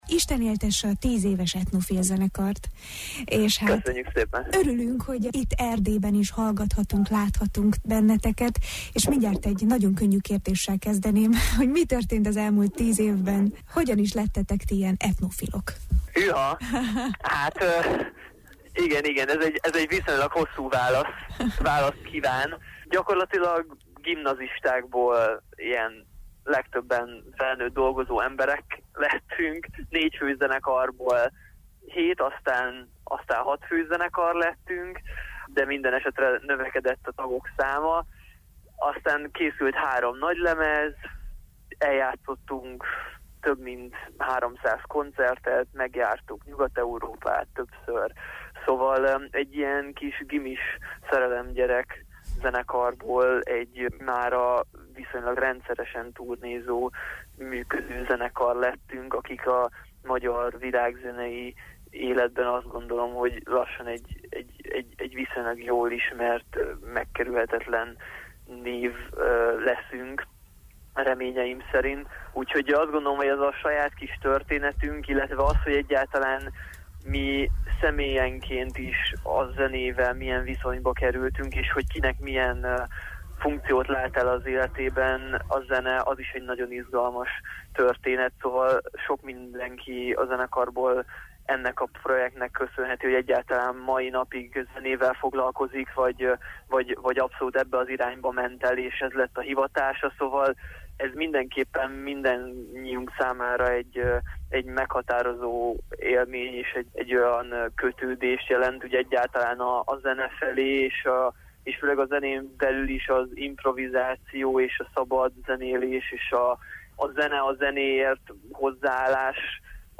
basszusgitár
ütőhangszerek
ének, hegedű
dobok
hangminták/effektek
fuvola, szaxofon